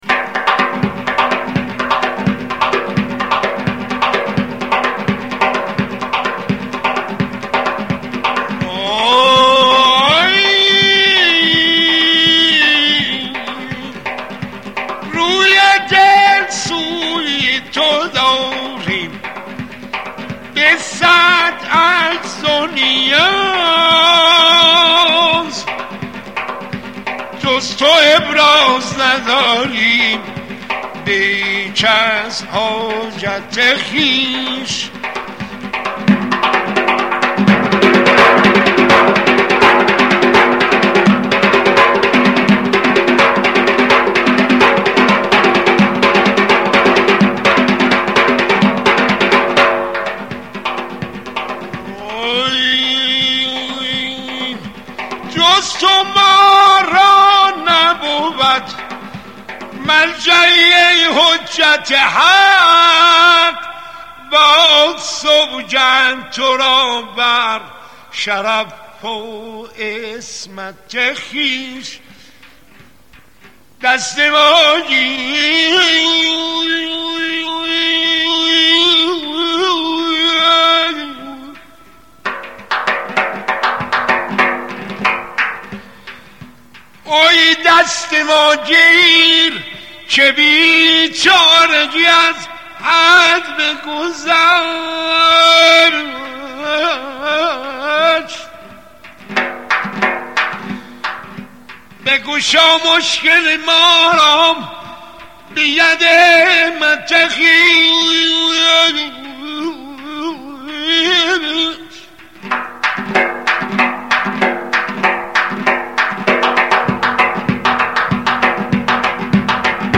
آهنگ زورخانه قدیمی
آهنگ صوتی برای ورزش باستانی